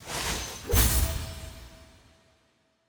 sfx-honor-postgame-votereceived.ogg